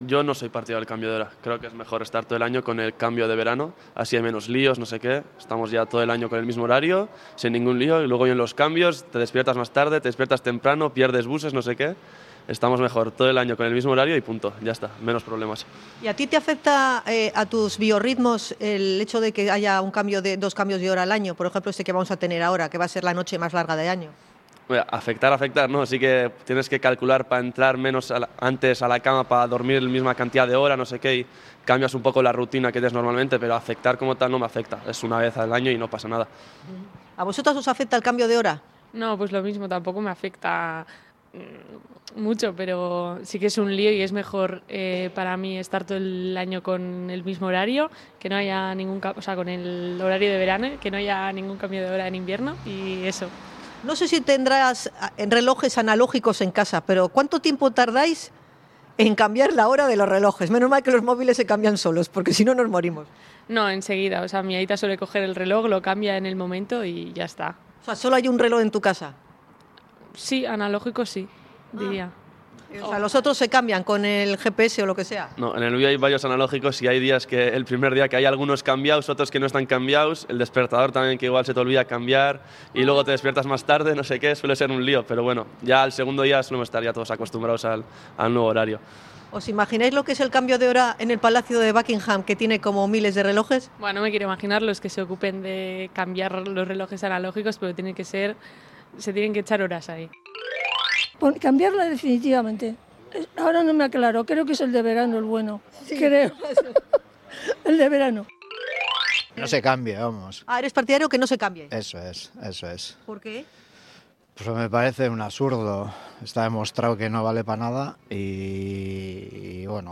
Y hemos salido a la calle para conocer las opiniones de los habitantes de Bilbao sobre este asunto.
ENCUESTA-CAMBIO-DE-HORA.mp3